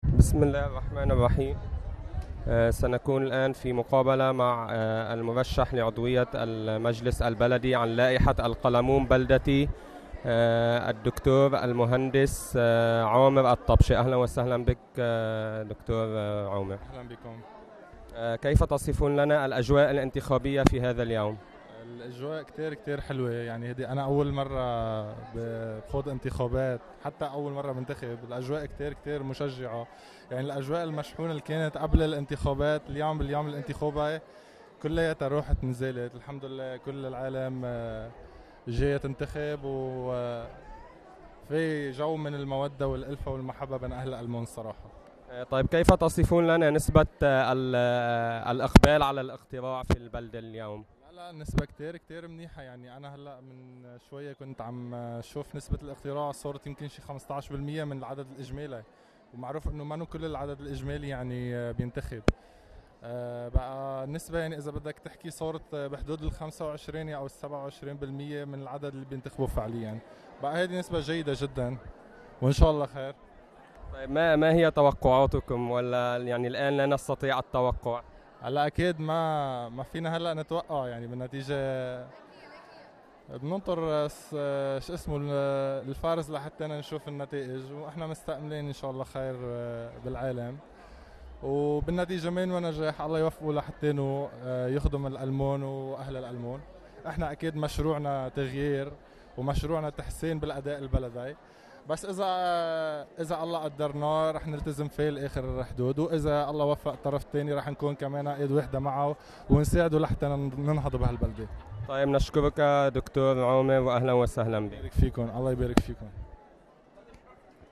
مقابلة مع المرشح عن المقعد البلدي